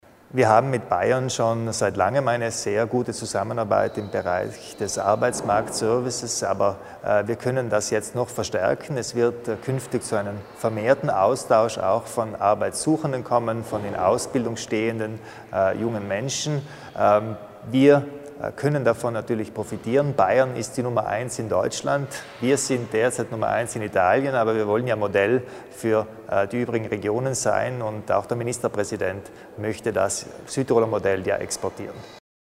Landesrätin Stocker zu den Details der Vereinbarung zwischen Südtirol und Bayern